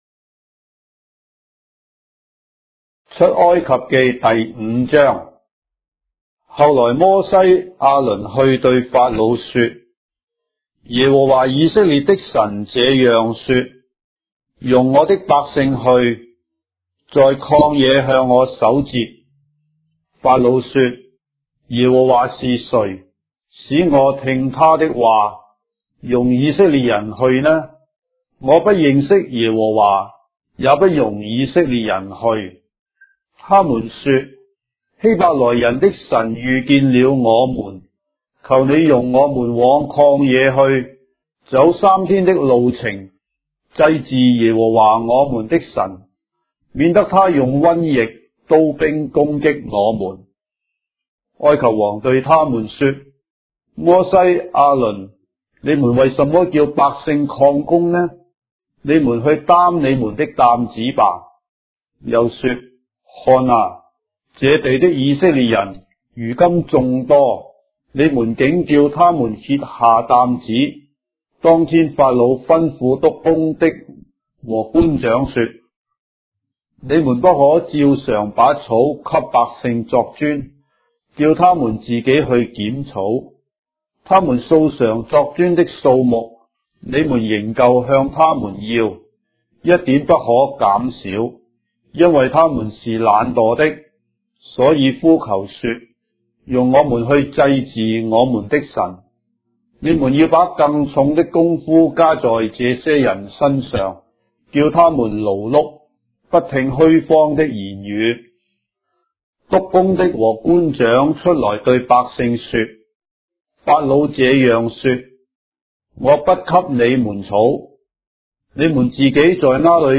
章的聖經在中國的語言，音頻旁白- Exodus, chapter 5 of the Holy Bible in Traditional Chinese